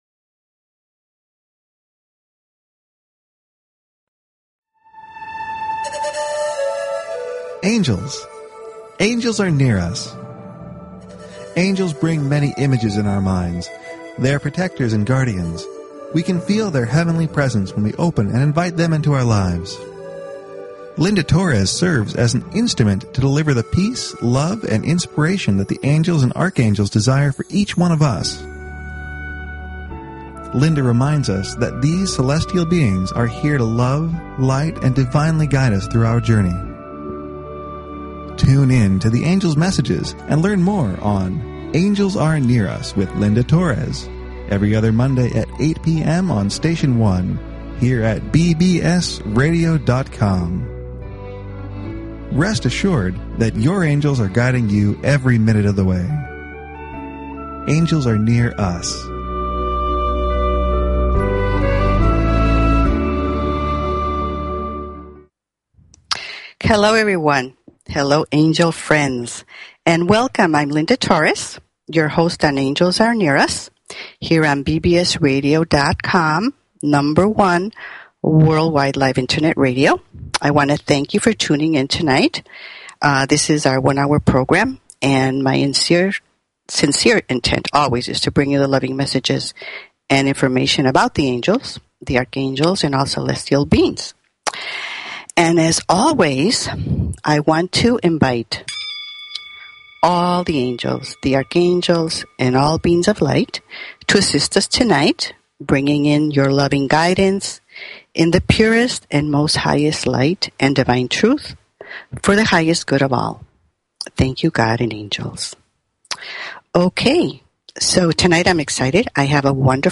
Talk Show Episode, Audio Podcast, Angels_Are_Near_Us and Courtesy of BBS Radio on , show guests , about , categorized as
The last 30 minutes of the show the phone lines will be open for questions and Angel readings.